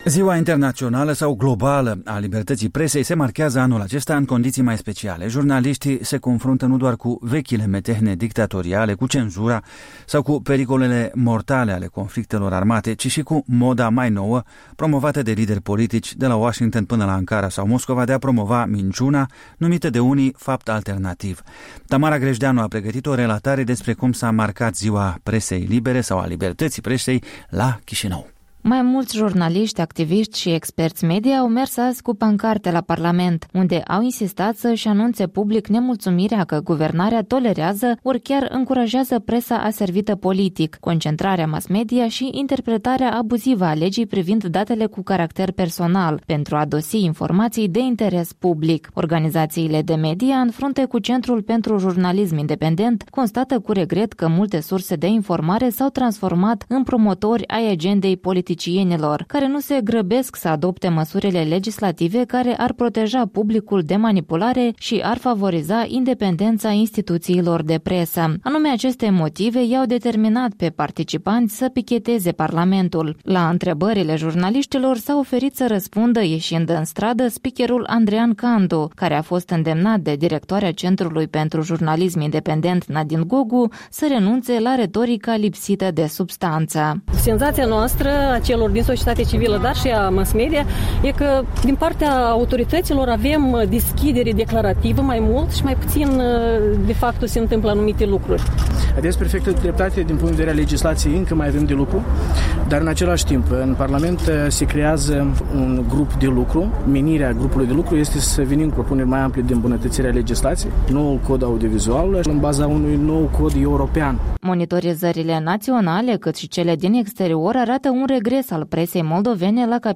Ziariștii moldoveni marchează Ziua Libertății Presei în fața Parlamentului de la Chișinău